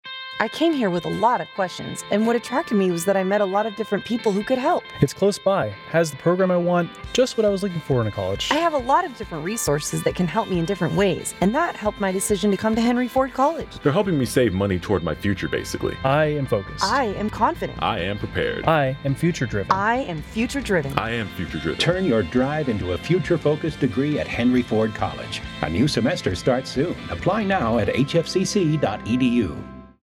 Radio/Audio Spots FY20-21